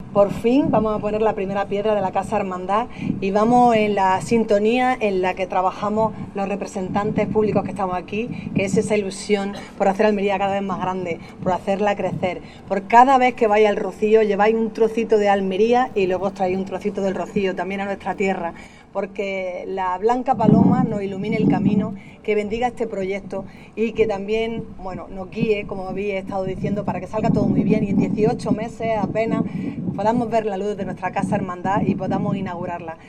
Ayuntamiento, Diputación y Junta de Andalucía acompañan a los rocieros en este acto simbólico que sirve como primer paso para levantar un edificio en la calle Atarazanas
25-06-casa-rocio-alcaldesa.mp3